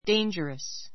dangerous 中 A2 déindʒərəs デ インヂャラ ス 形容詞 危険を与 あた える恐 おそ れのある , 危険な , 危ない 関連語 「危険」は danger .